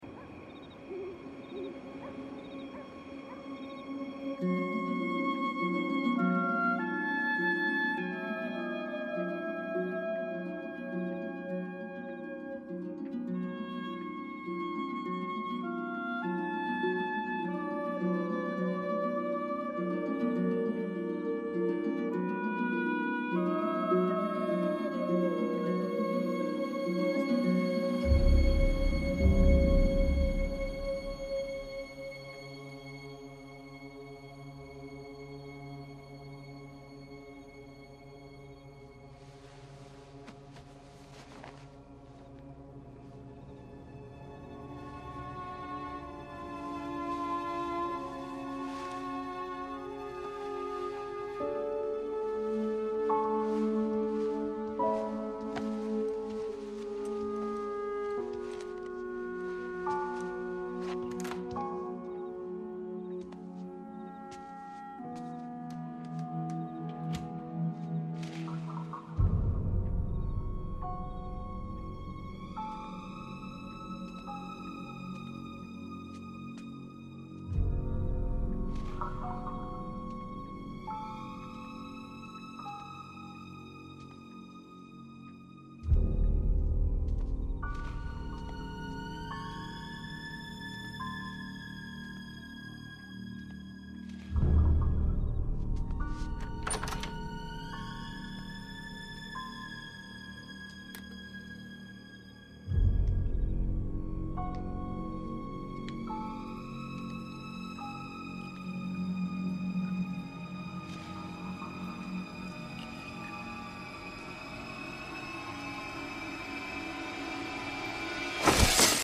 Musique issue de l’album: DVD  rip